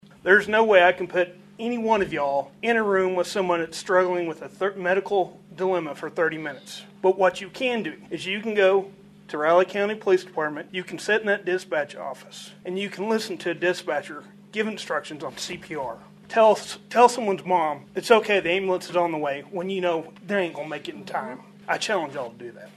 For years residents in northern Riley County have pushed for a facility, boiling over into a community meeting in early February 2022, where residents packed the Leonardville Community Building, sharing stories like this one about experiencing poor ambulance response times.